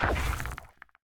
Minecraft Version Minecraft Version snapshot Latest Release | Latest Snapshot snapshot / assets / minecraft / sounds / mob / warden / step_4.ogg Compare With Compare With Latest Release | Latest Snapshot
step_4.ogg